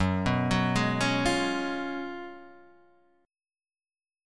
F#M7sus2sus4 Chord